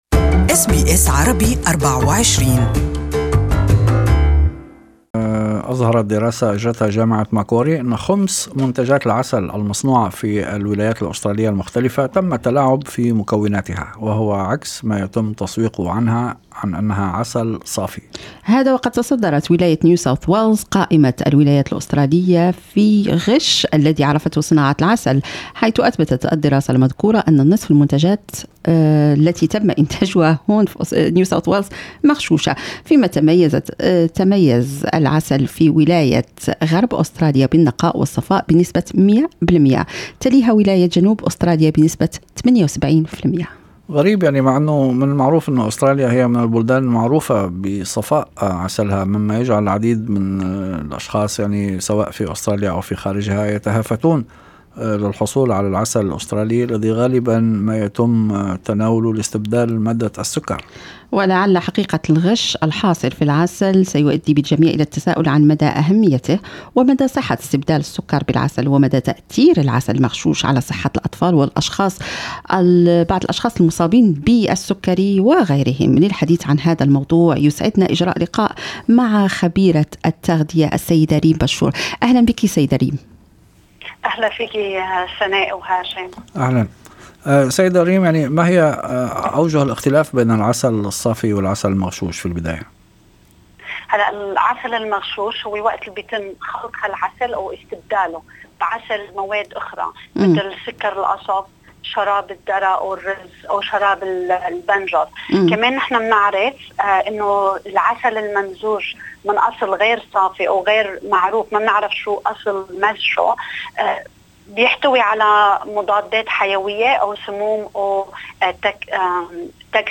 أجرى برنامج أستراليا اليوم لقاء مع خبيرة التغذية